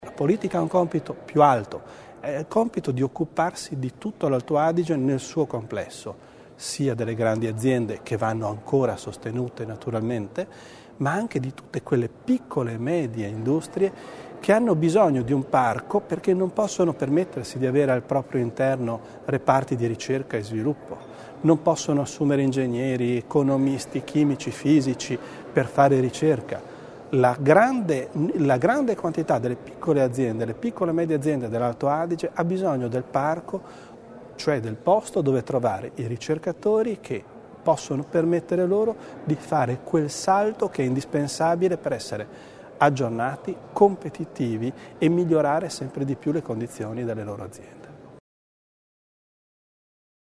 Con queste parole l'assessore provinciale Roberto Bizzo, durante il colloquio di metà legislatura, ha introdotto uno dei temi chiave per il futuro della Provincia di Bolzano.